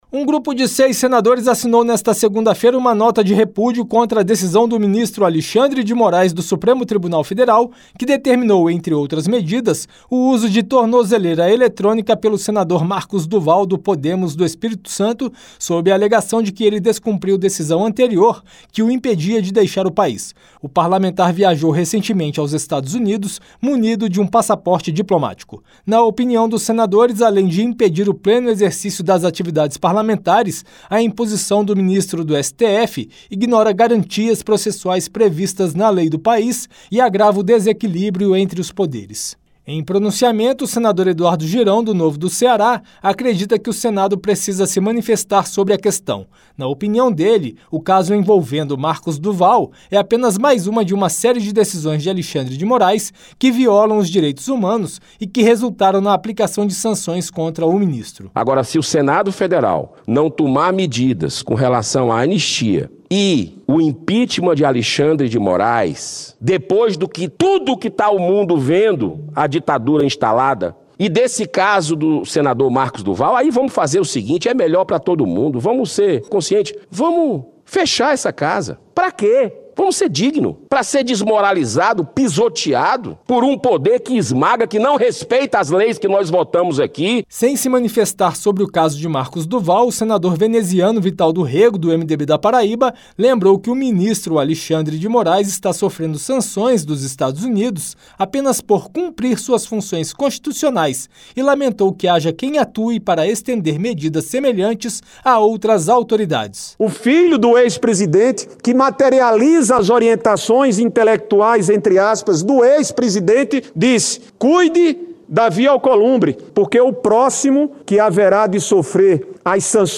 Pronunciamento